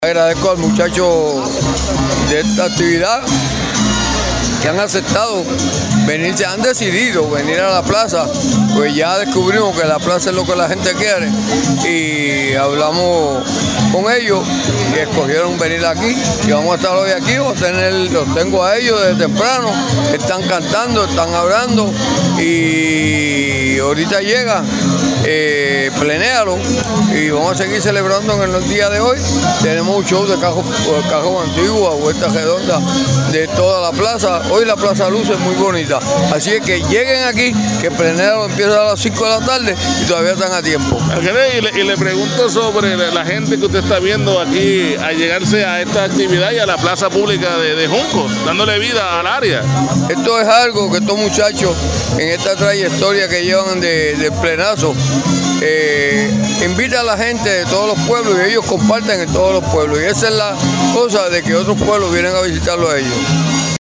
JUNCOS, 21 de febrero De 2026) En una tarde marcada por el orgullo cultural y la unidad comunitaria, el Municipio Autónomo de Juncos celebró con gran éxito la decimotercera edición del Plenazo Junqueño, convirtiendo la Plaza de Recreo Antonio R. Barceló en el epicentro de la tradición puertorriqueña este sábado, 21 de febrero.